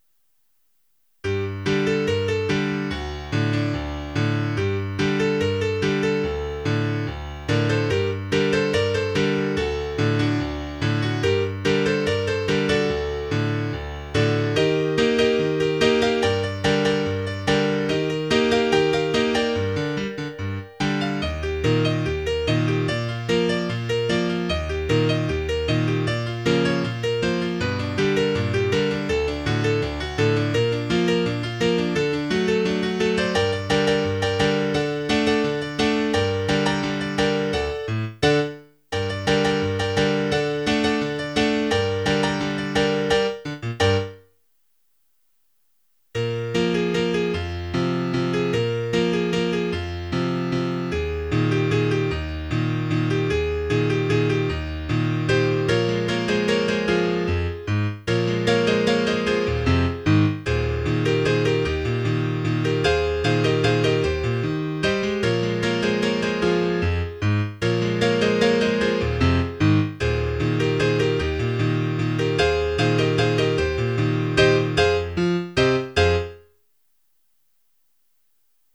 A sprightly little ditty
Solo, Piano